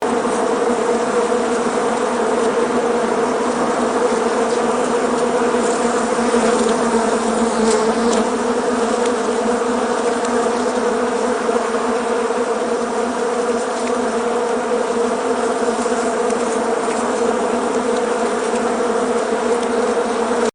Free SFX sound effect: Super Swarm.